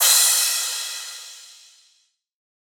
Cym (EDM-Acoustics).wav